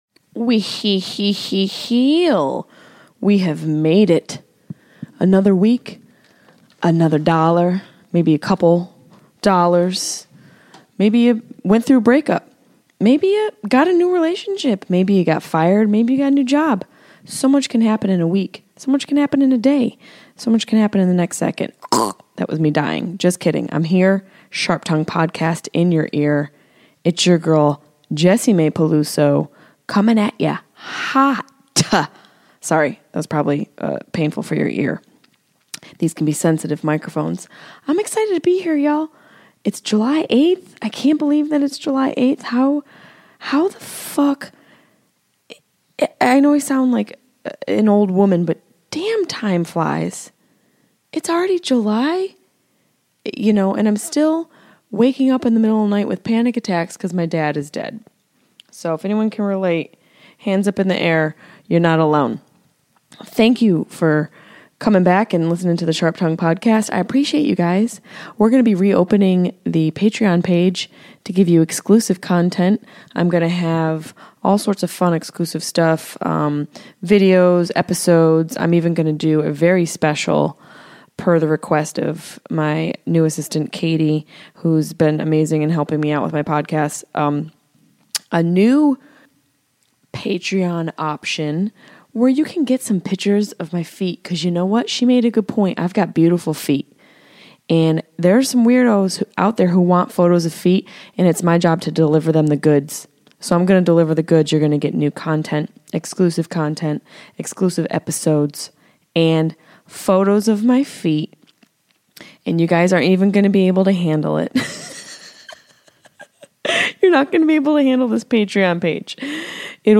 We discuss our time on MTV's Girl Code, Jeff Dye cuts Jessimae off repeatedly as he complains about female comics complaining, plus we tell you why you should do drugs.